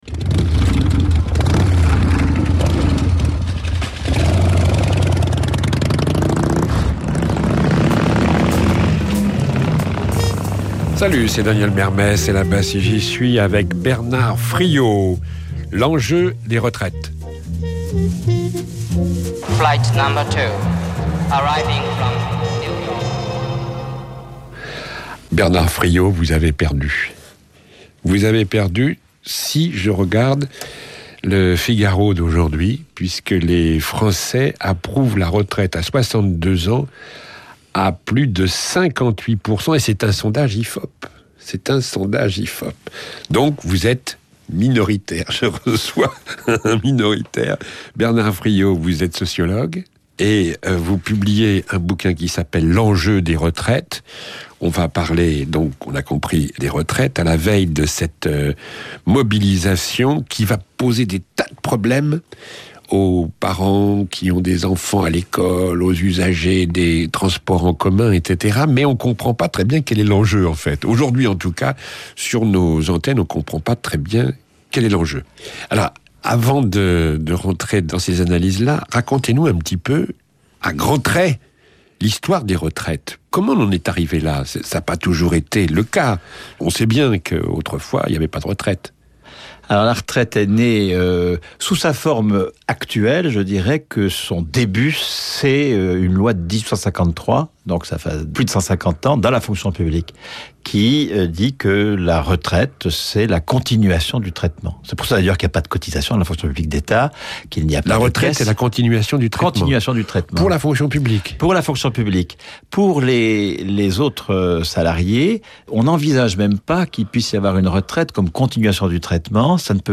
Retour L'enjeu des retraites France Inter le 23 juin 2010, émission « Las bas si j'y suis» de Daniel Mermet, 40 minutes. Cours magistral avec Bernard Friot, sociologue, économiste et auteur de "L’enjeu des retraites".